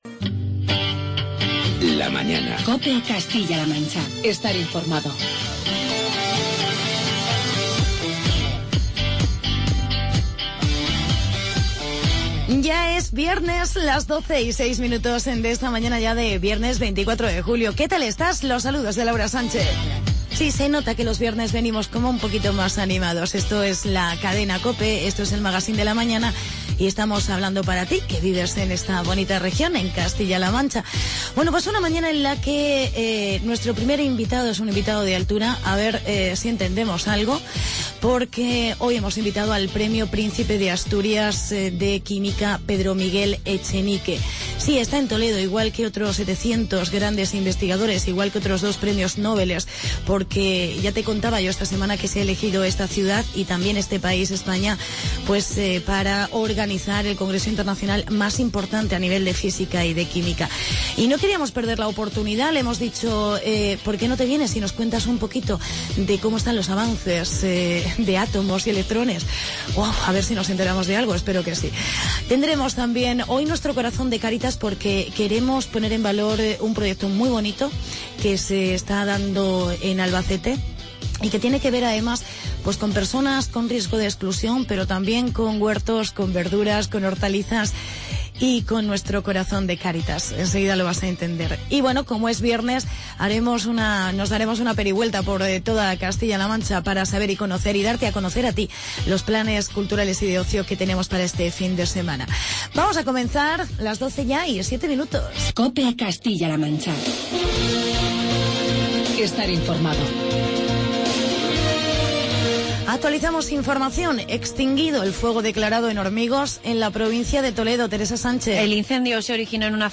Entrevista con Pedro Echenique, Premio Príncipe de Asturias. Hablamos con el Director General de Política Forestal, Rafael Cubero y Agenda regional.